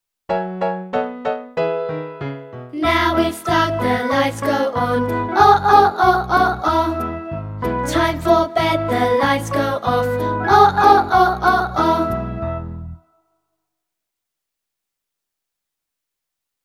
每个发音都配有一个用著名曲调填词的短歌和动作图示。